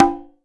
083 - CongaHi.wav